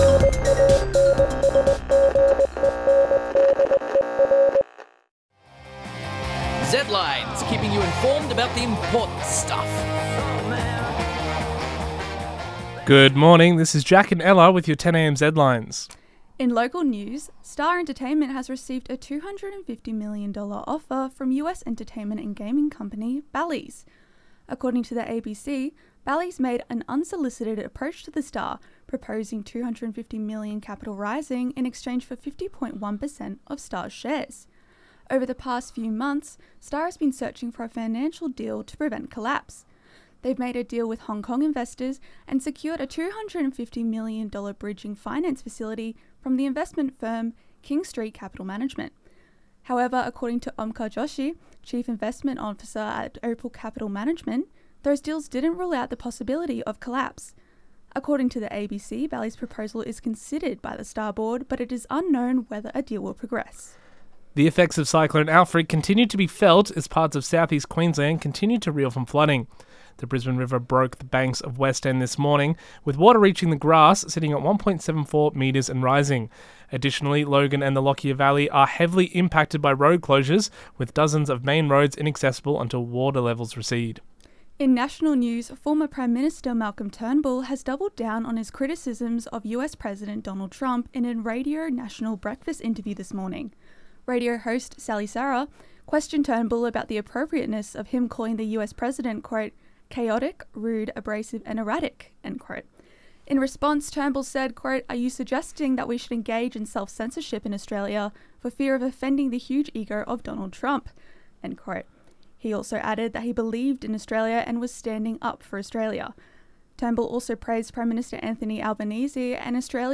Pope Francis (Catholic Church England and Wales/Flickr under CC BY-ND 2.0) Zedlines Bulletin 10AM ZEDLINES 11.3.25.mp3 (6.11 MB)